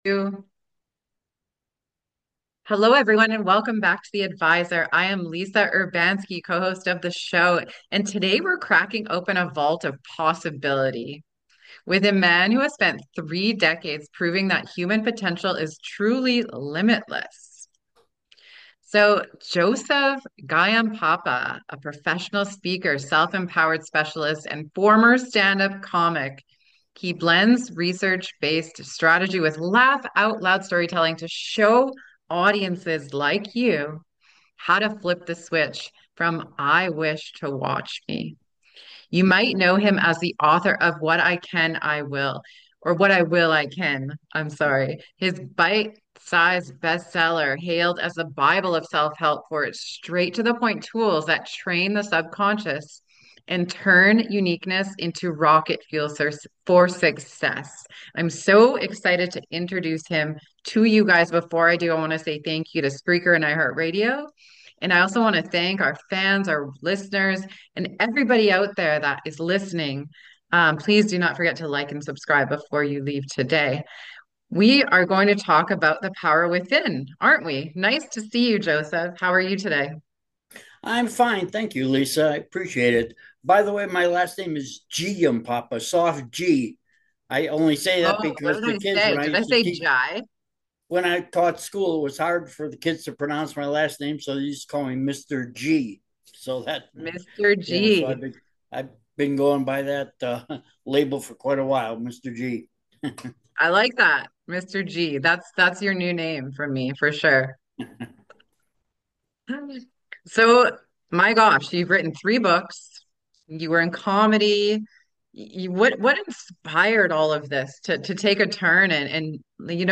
In this transformative conversation